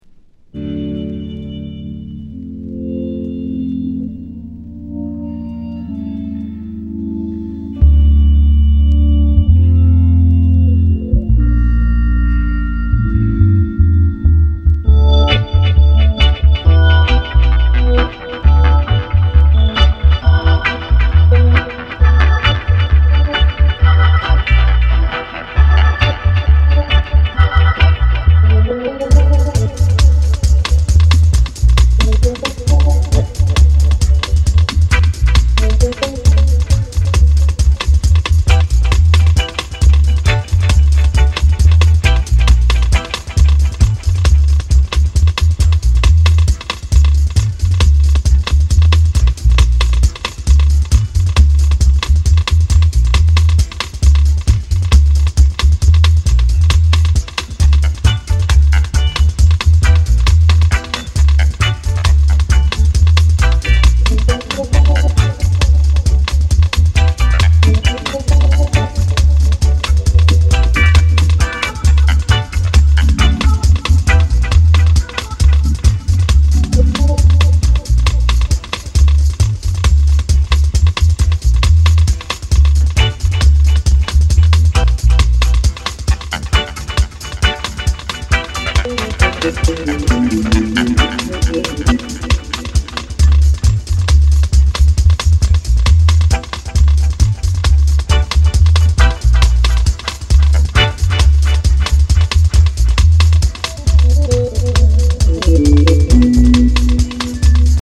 ダブステッパーズトロピカル